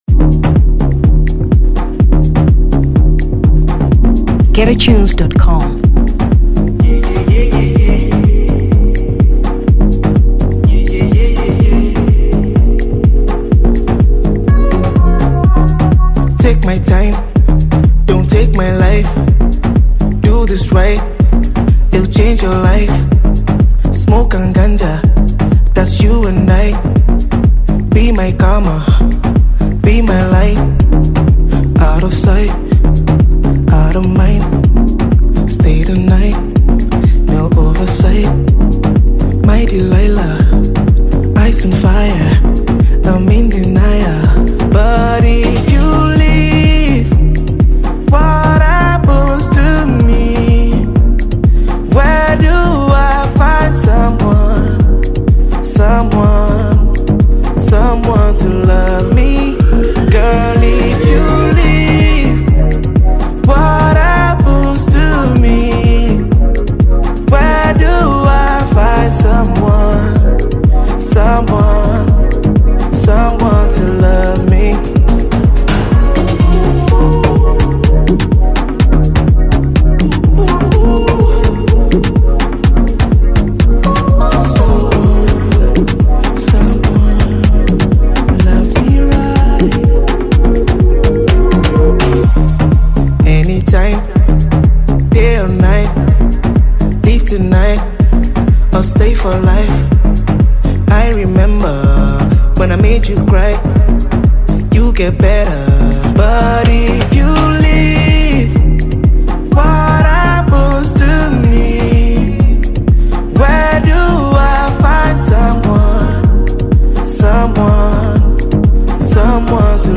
Afrobeat 2023 Nigeria